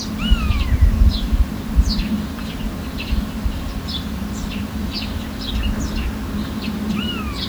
Eurasian Jay, Garrulus glandarius
StatusVoice, calls heard
Audio fails ir no aplikācijas ieraksta.